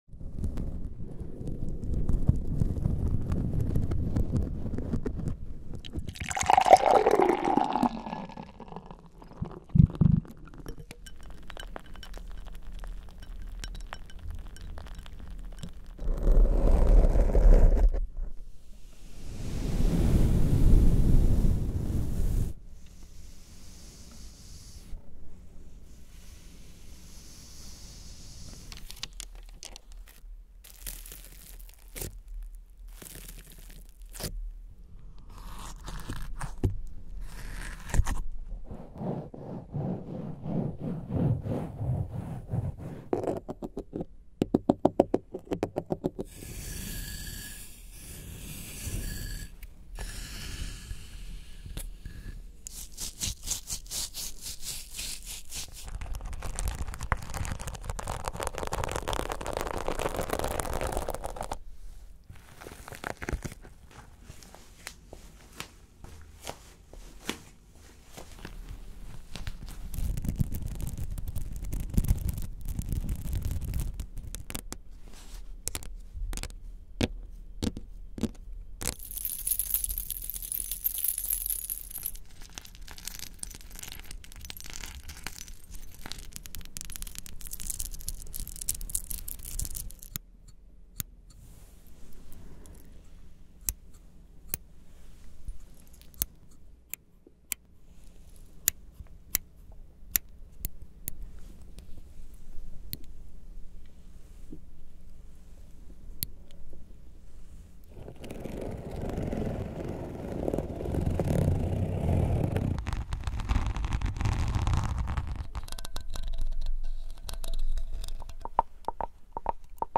ASMR You are GOLD, Baby👑 (Golden Triggers with My Soul, Tapping)-1DuyMAWwckU.m4a